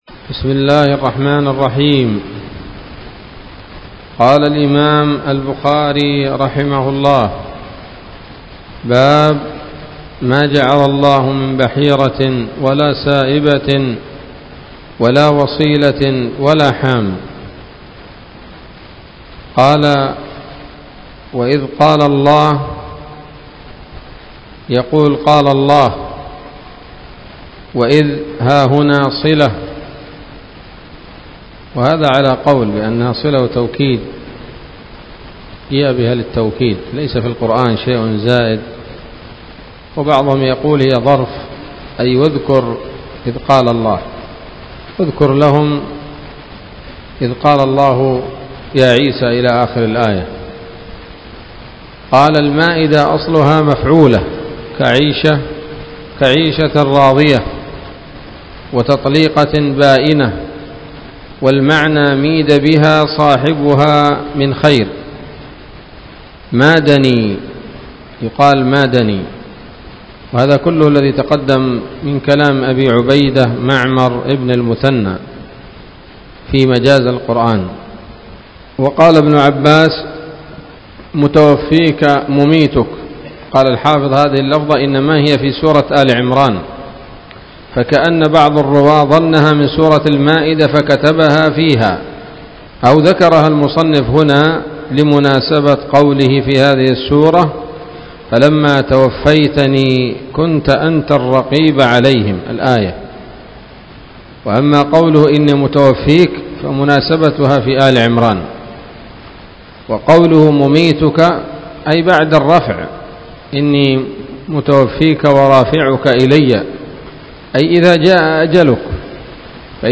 الدرس السادس والتسعون من كتاب التفسير من صحيح الإمام البخاري